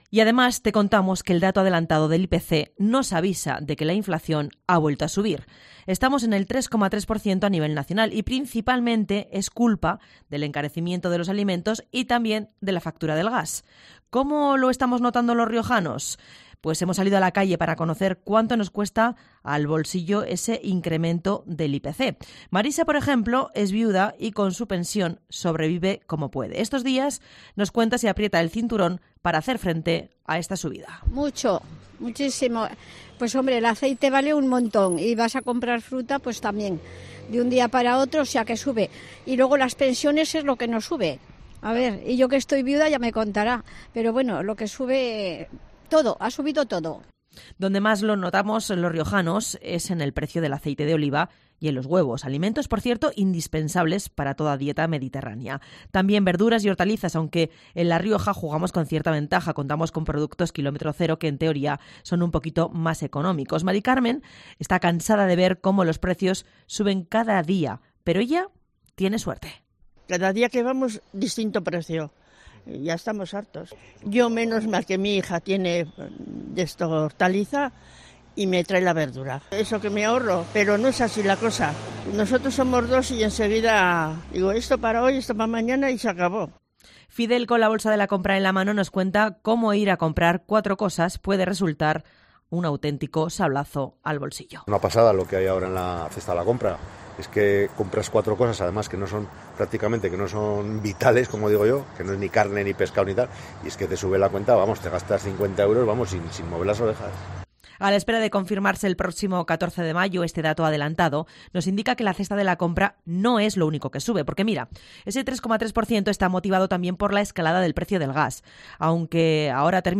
Hemos salido a la calle para conocer cuánto nos cuesta al bolsillo.